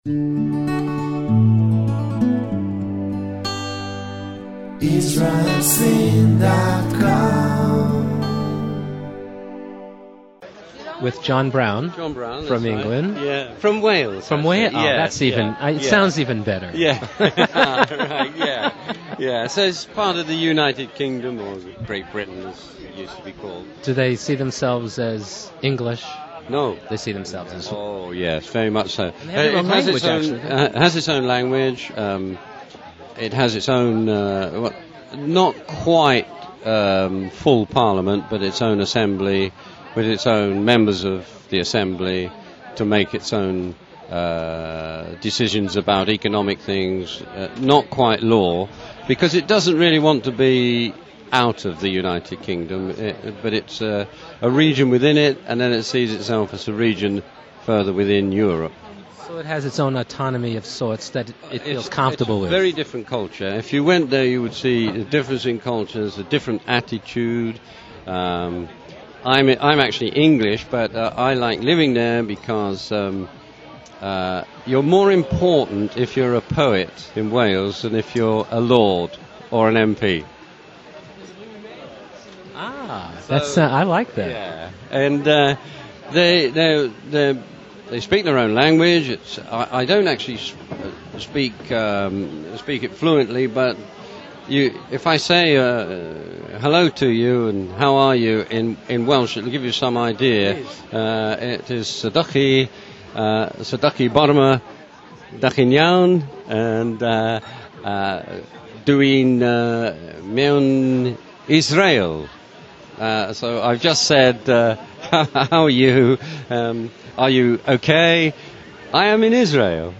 in the last of the series from the AP Art Symposium 2007 at Ein Hod, Israel.